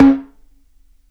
SNARE 2 OFF.wav